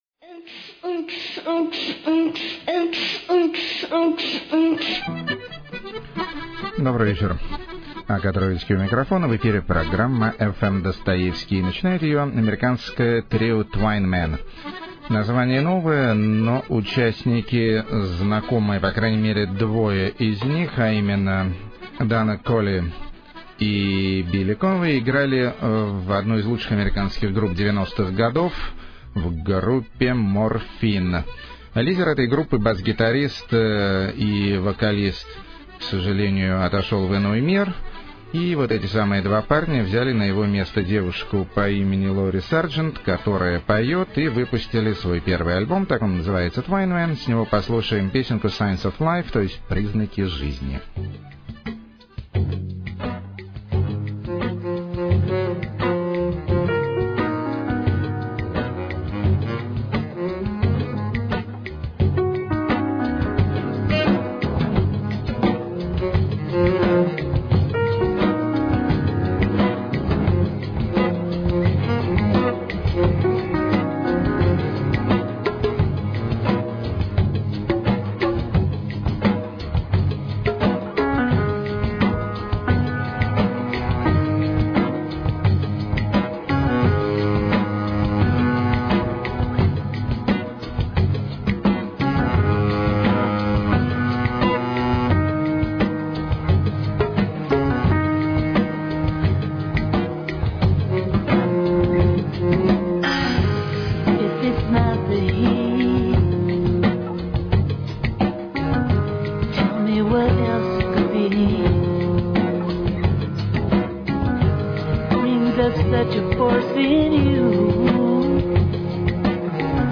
Ethno-dub
Techno-swing??
Gypceltic
Electrogotica